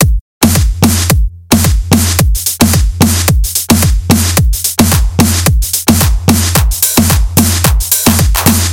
更难的电子音乐的FX循环 " 疯狂的FX循环
描述：2 bar循环，138bpm，24bit，48khz，wav
Tag: 最小 怪异 狂野 循环 TECHNO 俱乐部 房子 外汇 毛刺跳